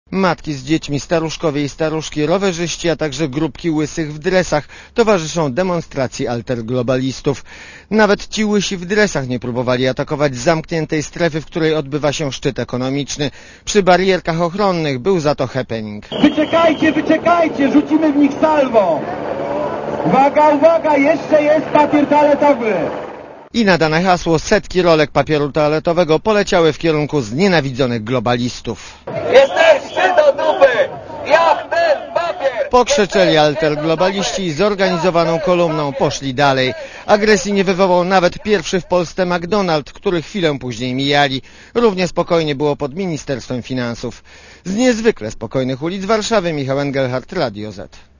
Pochód otwierają bębniarze skandujący "Wojnie precz".
W tle policyjne syreny.
Relacja reportera Radia ZET
demonstracjaidzie.mp3